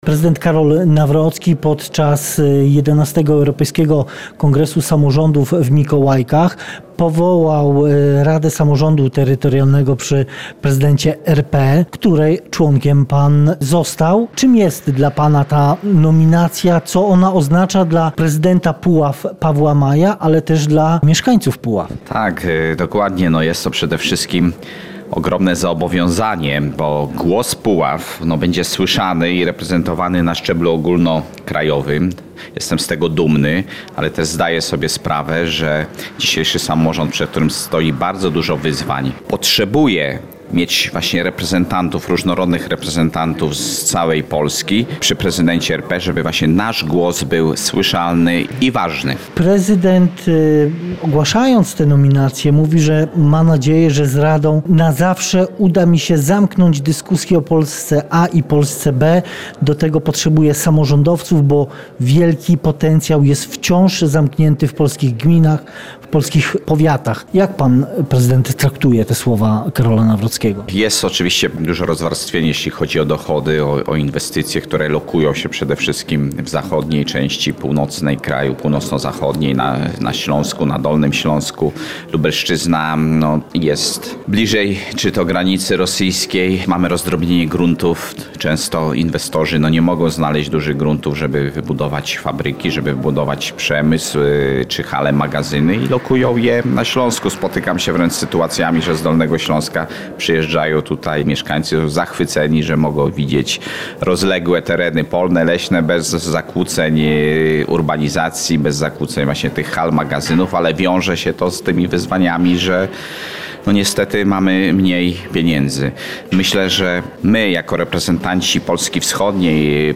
Rozmowa z prezydentem Puław Pawłem Majem